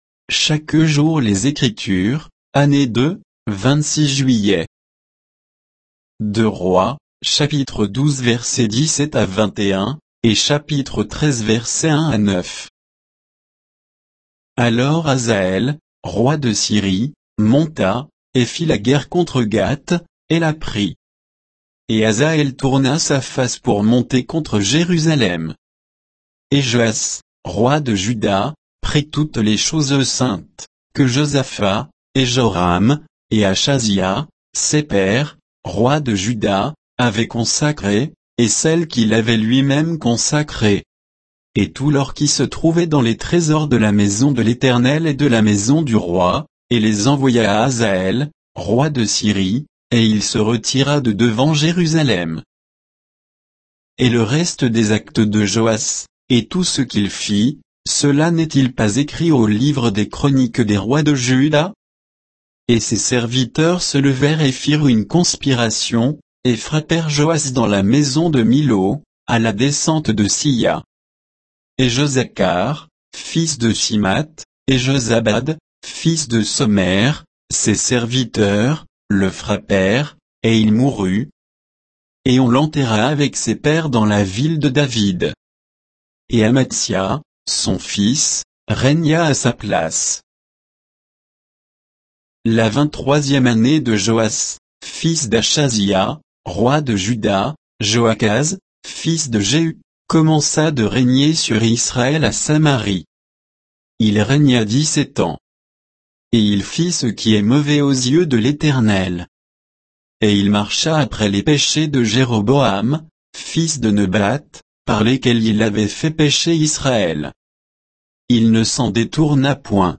Méditation quoditienne de Chaque jour les Écritures sur 2 Rois 12, 17 à 13, 9